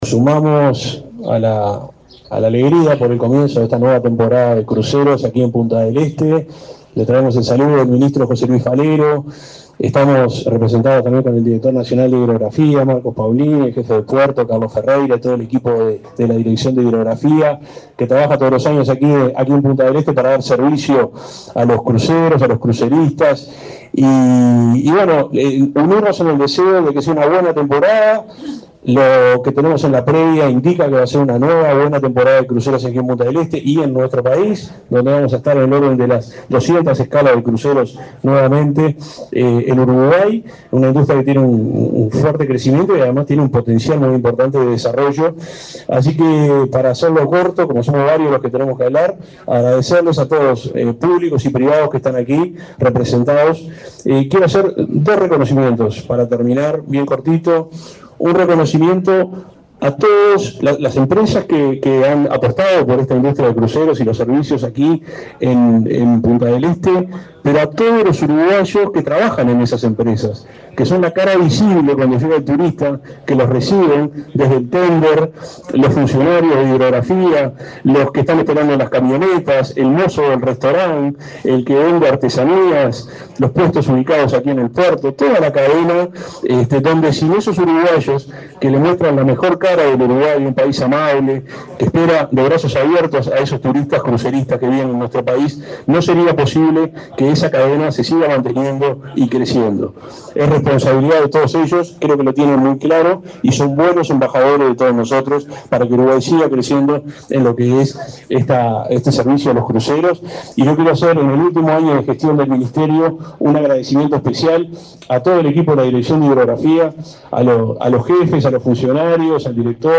Palabras del subsecretario de Transporte, Juan José Olaizola
Palabras del subsecretario de Transporte, Juan José Olaizola 12/12/2024 Compartir Facebook X Copiar enlace WhatsApp LinkedIn Este jueves 12 en Punta del Este, Maldonado, el subsecretario de Transporte, Juan José Olaizola, participó del lanzamiento de la temporada de cruceros 2024-2025.